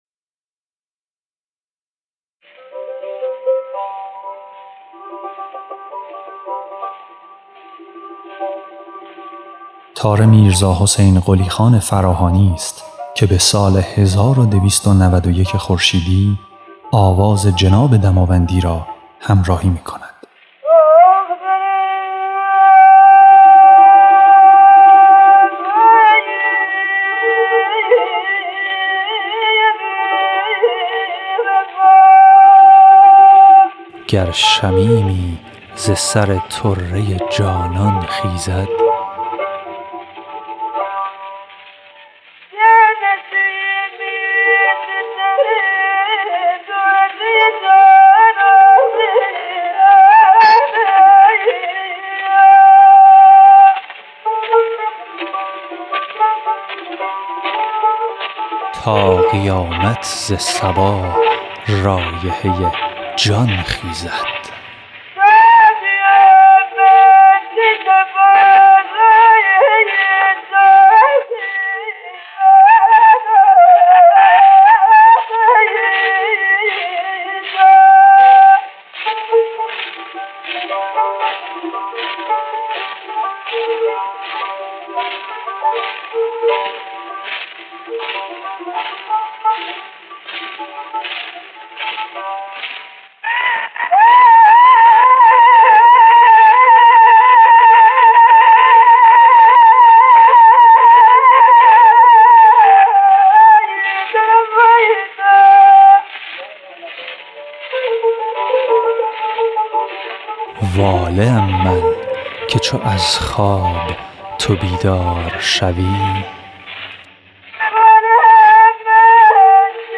خواننده
نوازنده تار